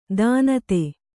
♪ dānate